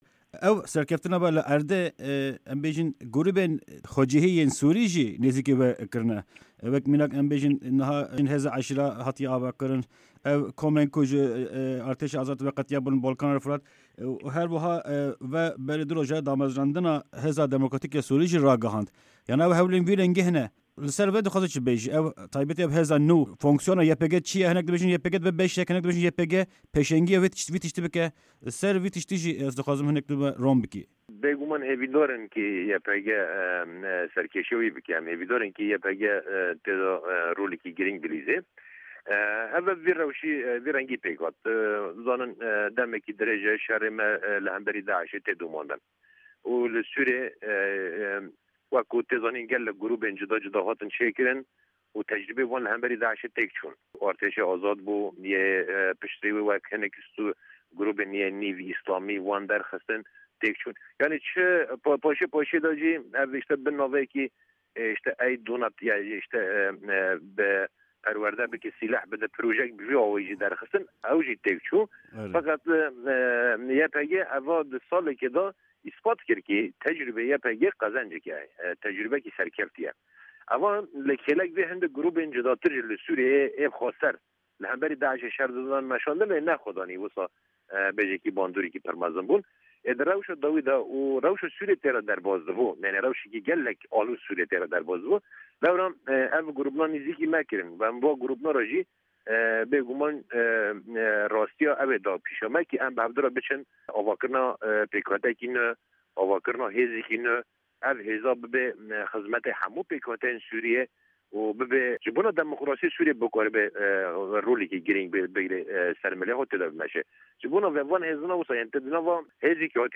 Di hevpeyvîneke taybet de Fermandarê Giştî yê Yekîneyên Parastina Gel (YPG), Sîpan Hemo li ser jimareke mijarên girîng yên wergirtina çekan ji Amerîka, êrîşên esmanî yên Rûsî û raporta Lêxweşbûna Navnetewî bersîva pirsên Dengê Amerîka dide.